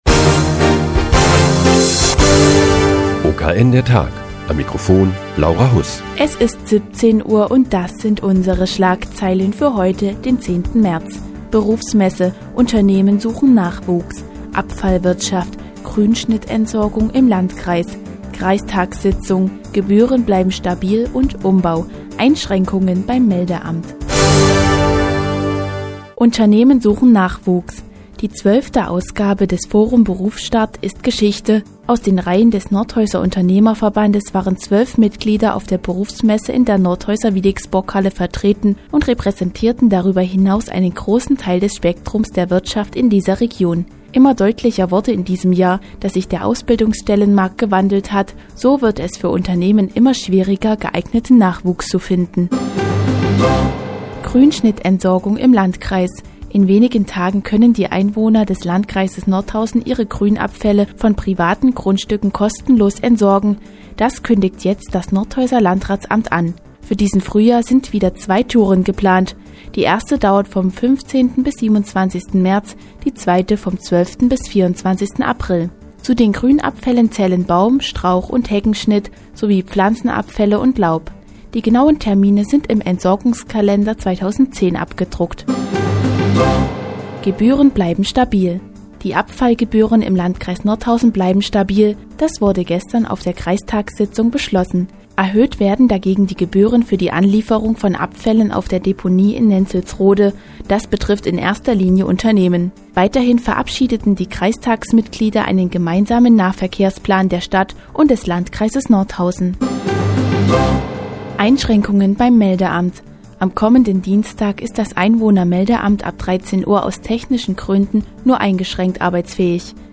Die tägliche Nachrichtensendung des OKN ist nun auch in der nnz zu hören. Heute geht es um die Grünschnittentsorgung im Landkreis und Einschränkungen beim Einwohnermeldeamt.